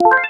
select_level.wav